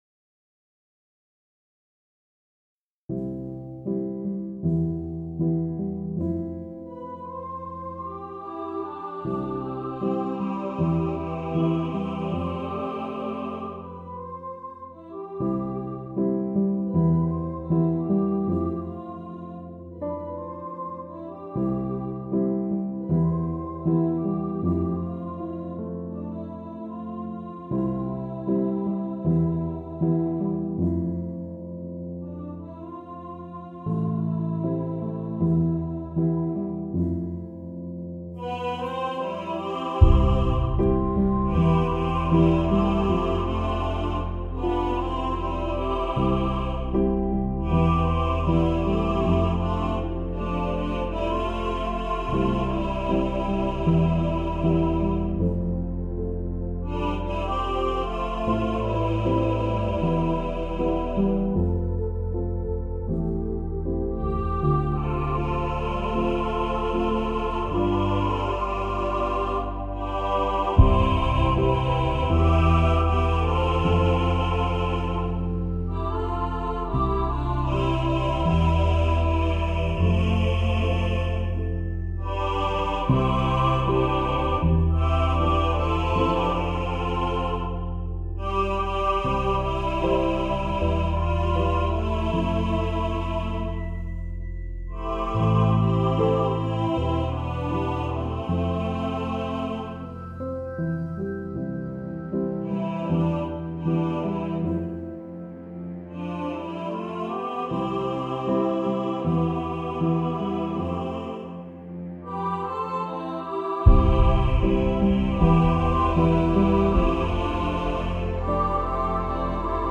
What Was I Made For – All Voices | Ipswich Hospital Community Choir